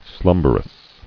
[slum·ber·ous]